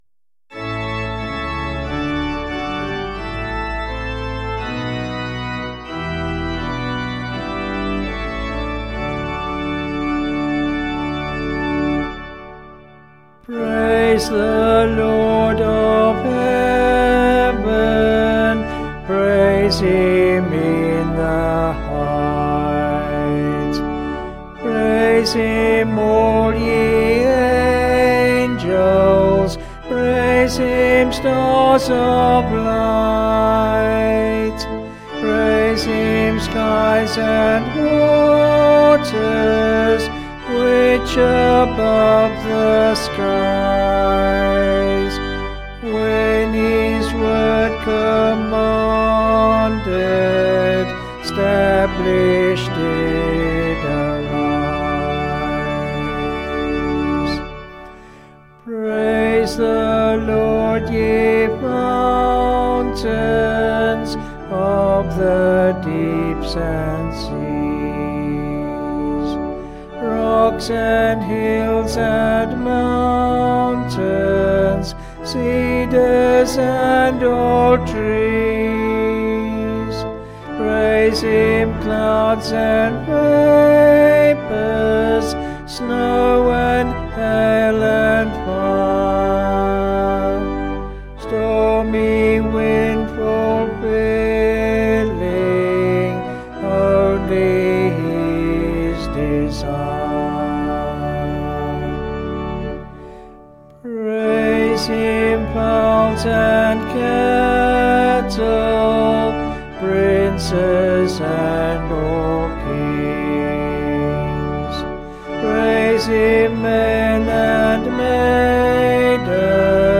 Vocals and Organ   265kb Sung Lyrics